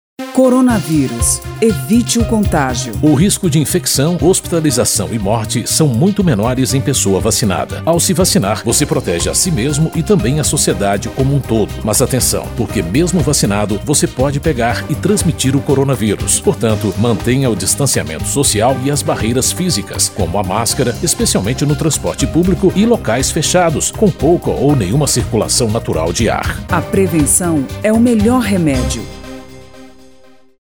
spot-vacina-coronavirus-2-1.mp3